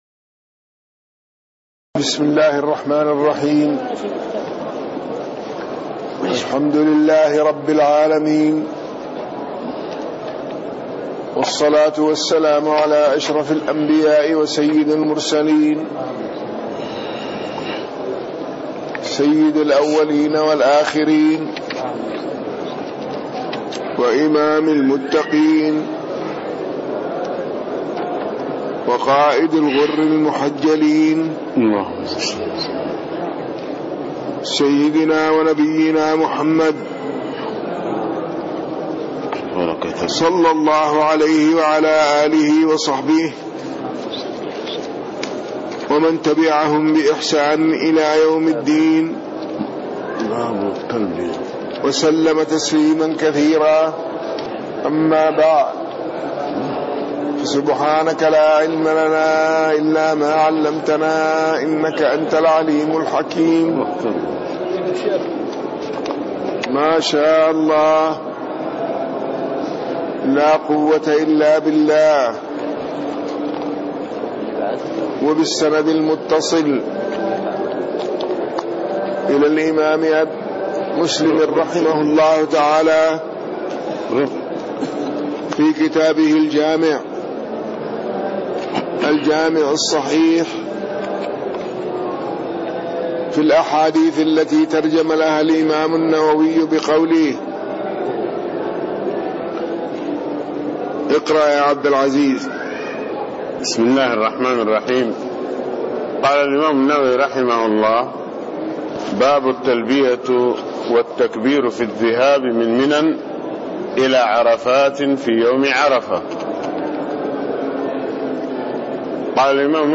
تاريخ النشر ٢٦ صفر ١٤٣٤ هـ المكان: المسجد النبوي الشيخ